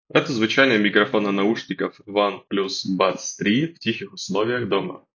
Микрофон OnePlus Buds 3 на 9 из 10 — отличный, как в шумных, так и в тихих условиях.
В тихих условиях: